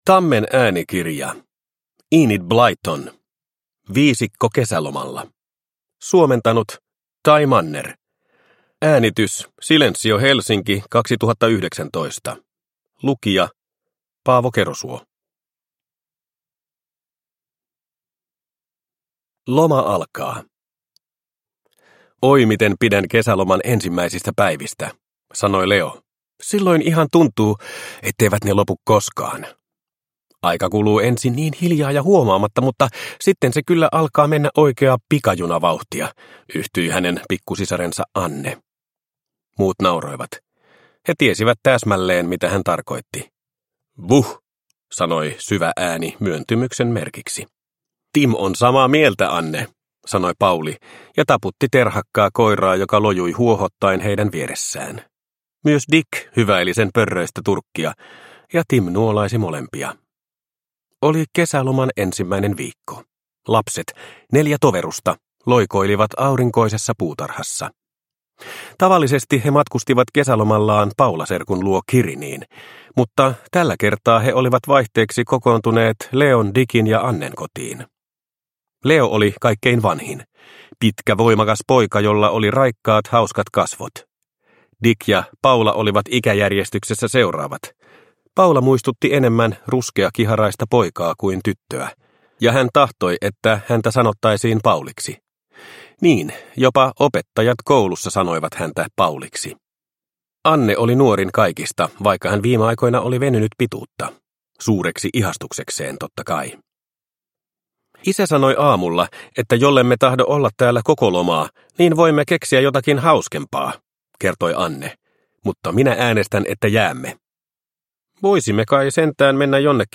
Viisikko kesälomalla – Ljudbok – Laddas ner